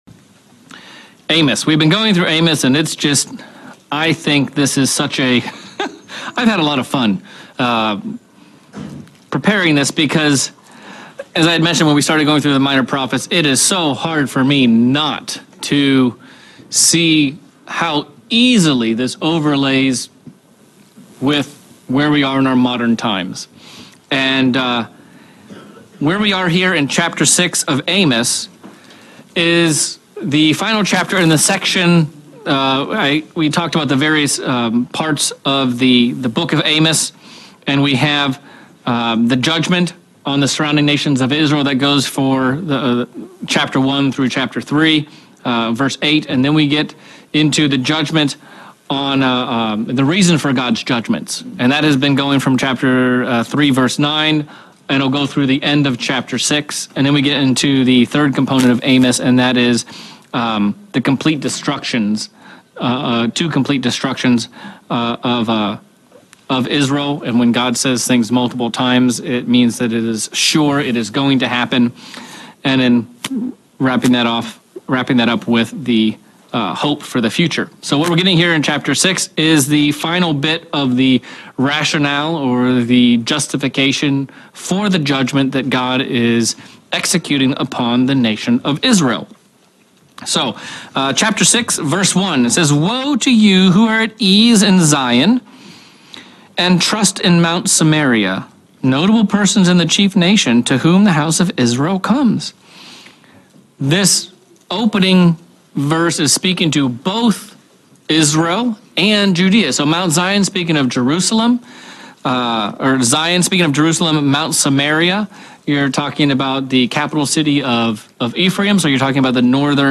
February 2024 Bible Study-Amos 6